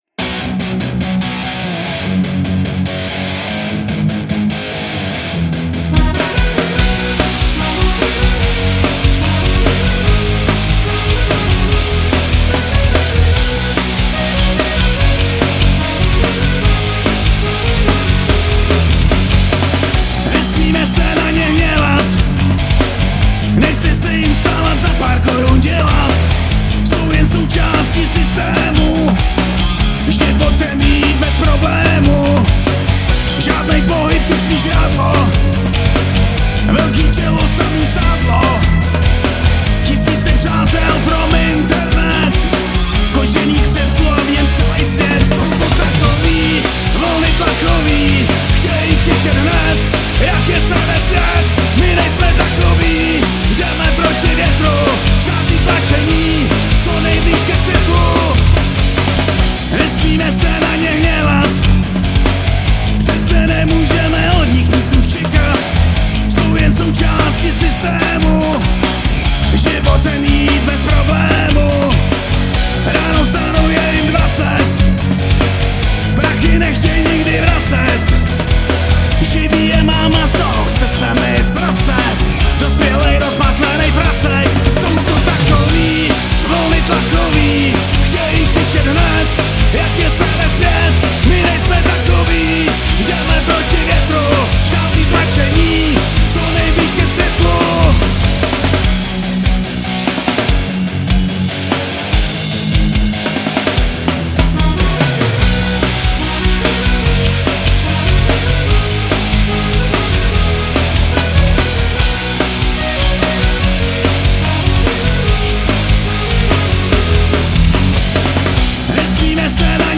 Žánr: Punk